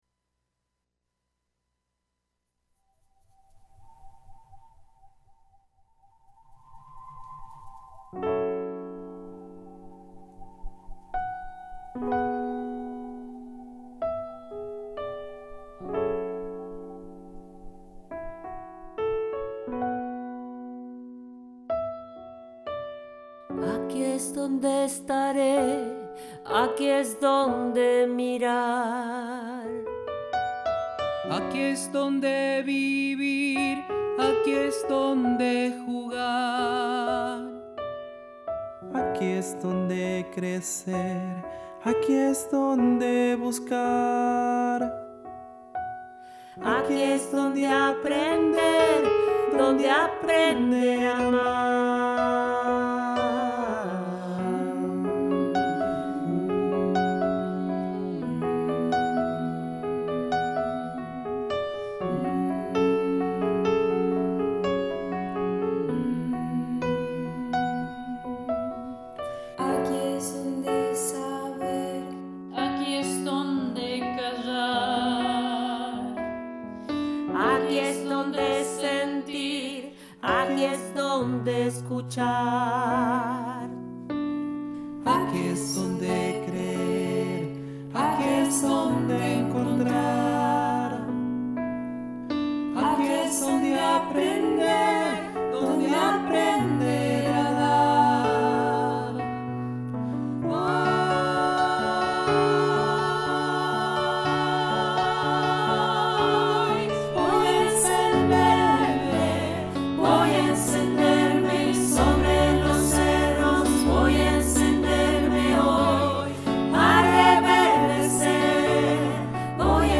Piano -ejecución y arreglos
Estudiantes de canto